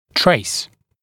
[treɪs][трэйс]проводить оценку, анализ (цефалограммы)